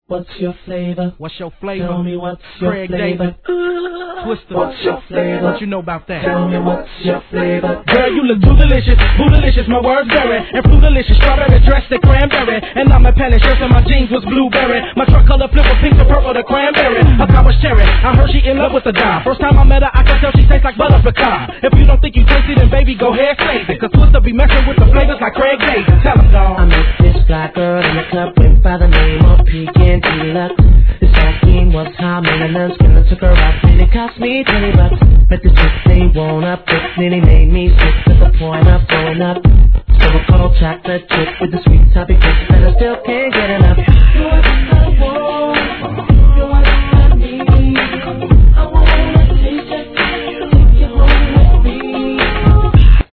HIP HOP/R&B
ヴォコーダーを使うダンサブルな2002年作品!